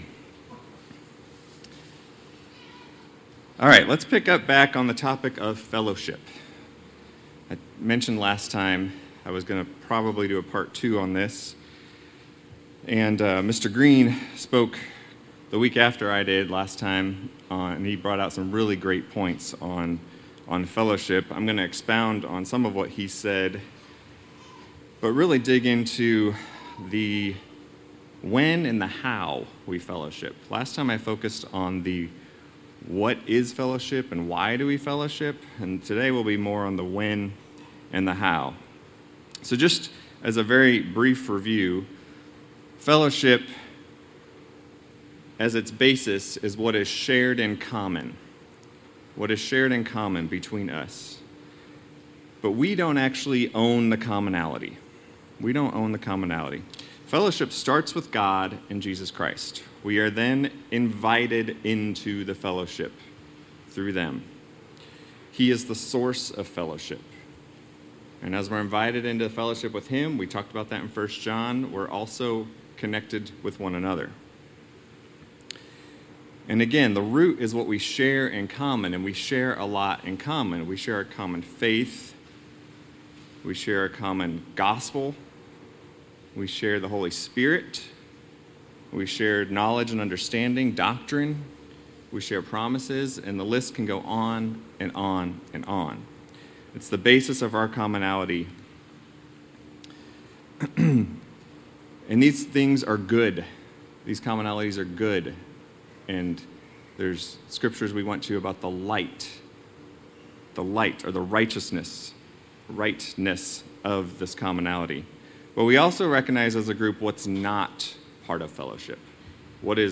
This is part 2 of the sermon "Fellowship." He will be talking about the when and the how.